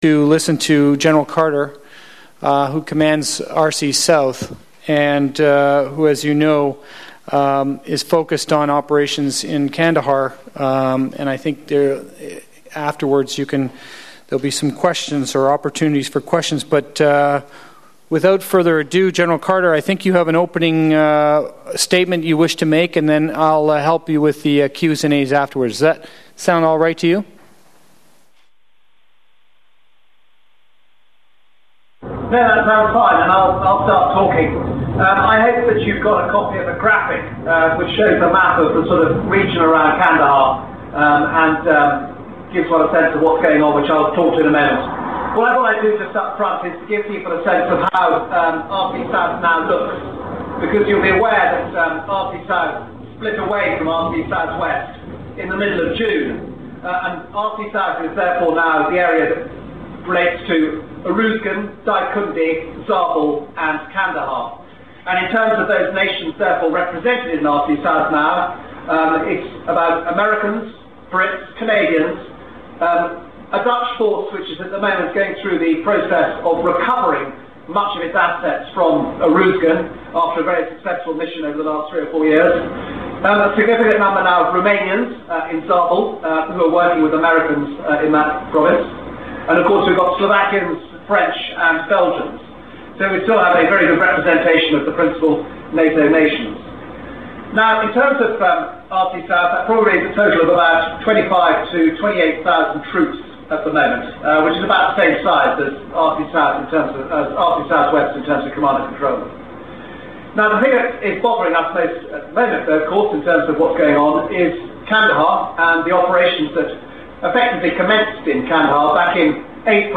Press briefing by Major General Nick Carter, Commander ISAF in Southern Afghanistan